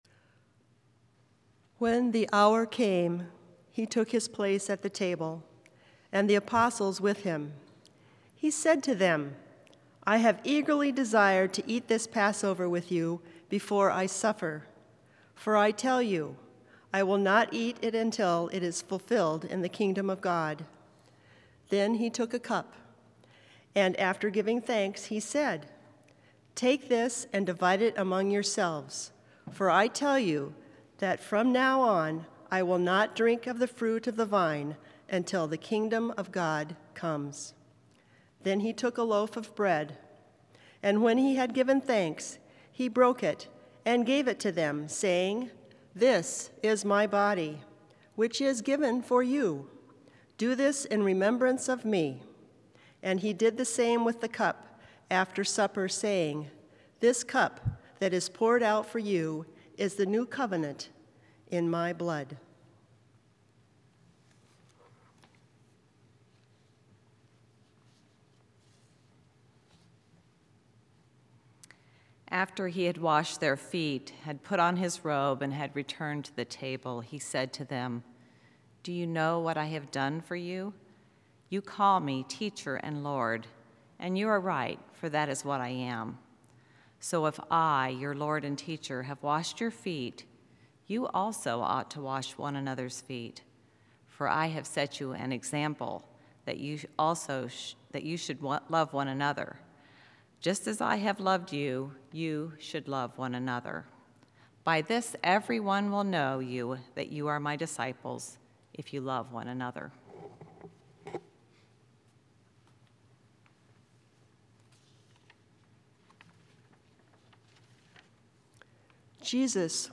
Sermon preached Good Friday, March 25, 2016
Texts: Tenebrae readings from John’s gospel